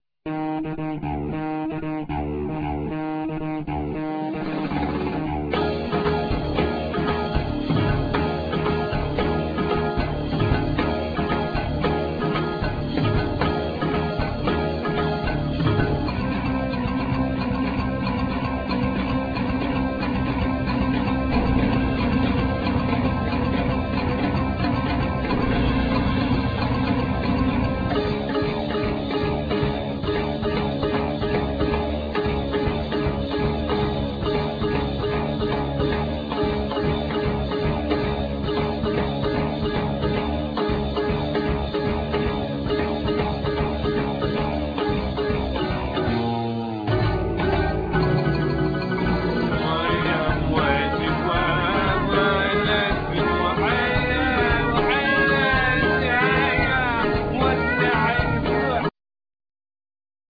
Keyboards
Voices
Saxophones
Recorder flute